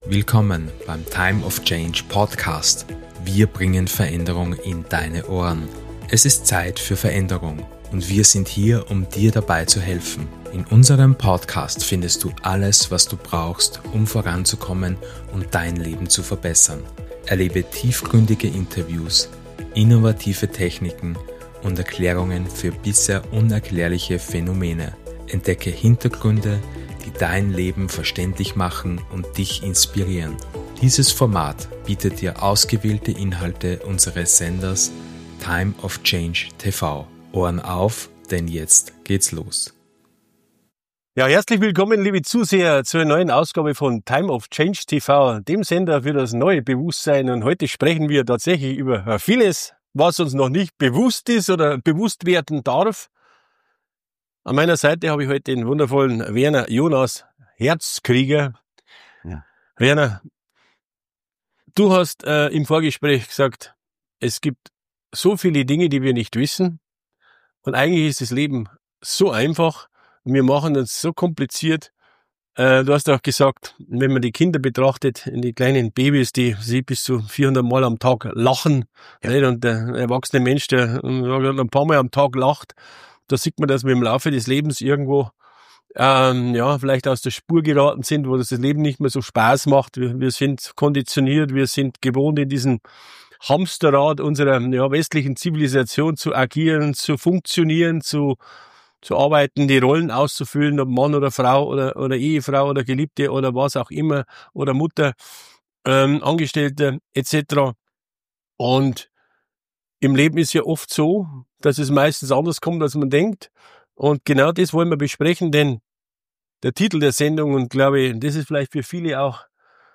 Das Interview bietet eine tiefgründige Auseinandersetzung mit Themen wie Spiritualität, Bewusstsein und persönlicher Entwicklung.